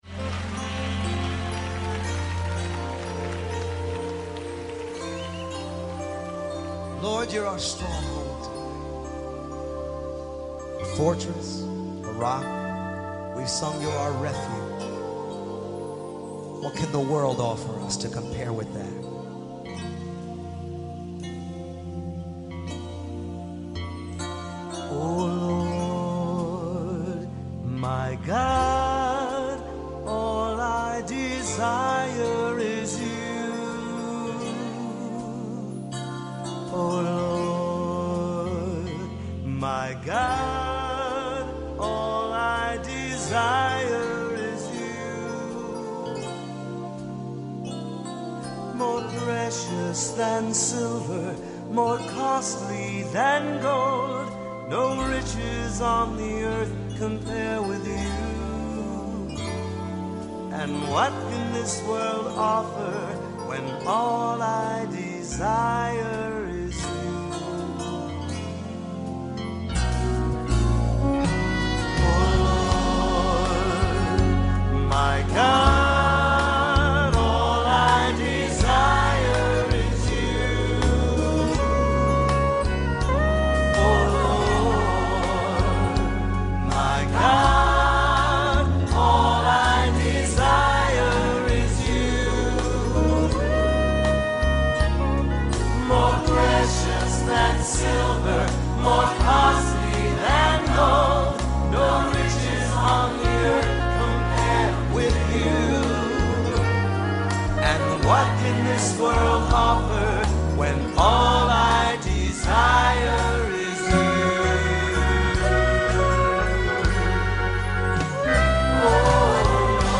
Insights, Worship, Personal, Ministry, Music, Testimony